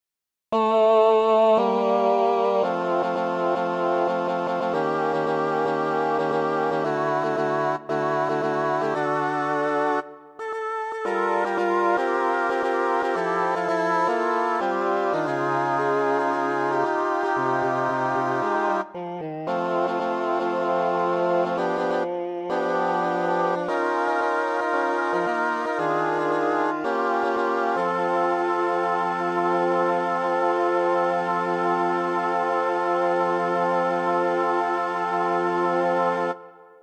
Key written in: F Major
How many parts: 4
Type: Barbershop
All Parts mix:
All parts recorded using Smule